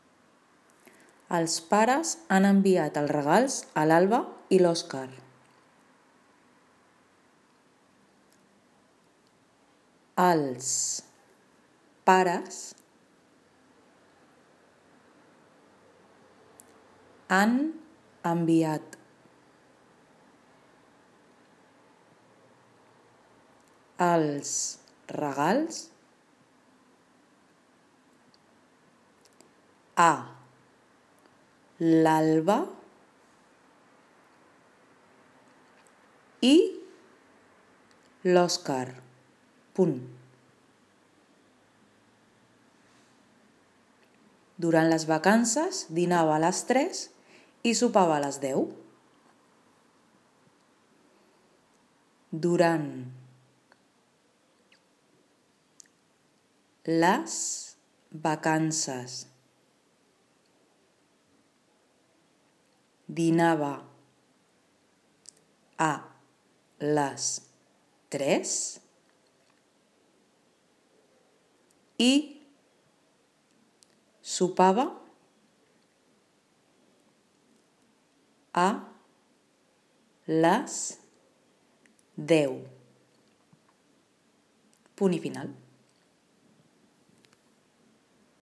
EXERCICI 3: DICTAT.
Recordeu que primer el llegiré tot sencer i després aniré parant.